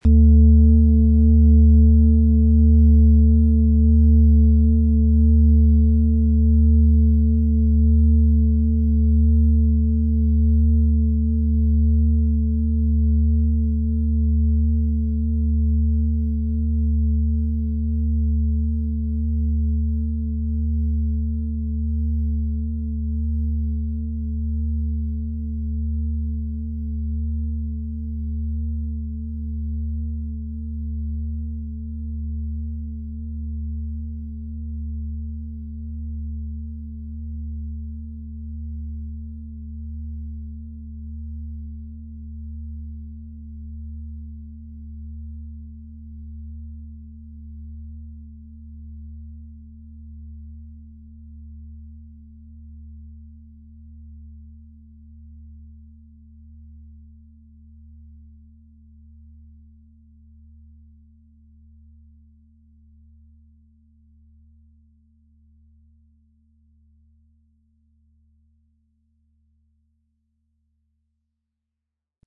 XXXL Fussreflexzonenschale mit Planetenton Thetawelle - inneren Frieden und Ausrichtung erfahren - bis Schuhgröße 43, Ø 47,5 cm, 10,15 kg, mit Klöppel
Ihre sanften Schwingungen führen dich in einen Zustand tiefer Entspannung – ideal für Meditation, Loslassen und innere Einkehr.
Um den Original-Klang genau dieser Schale zu hören, lassen Sie bitte den hinterlegten Sound abspielen.
PlanetentonThetawelle & Venus (Höchster Ton)
MaterialBronze